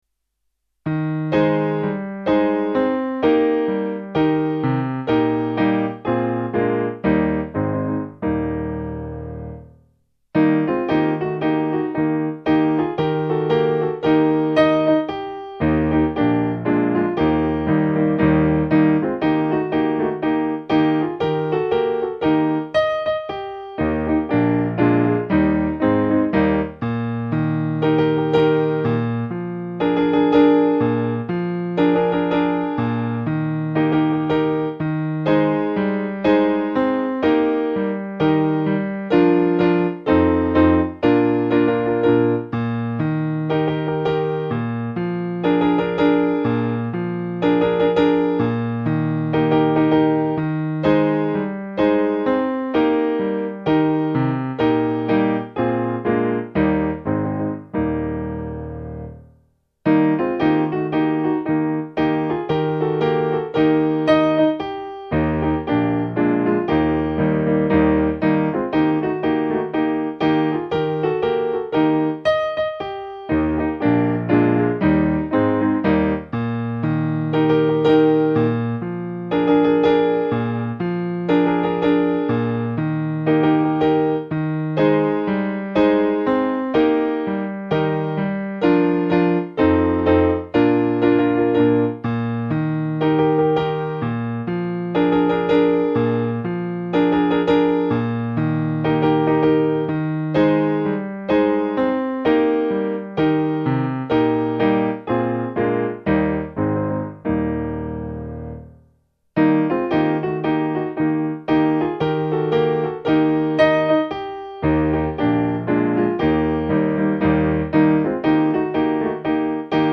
Key: E♭ Major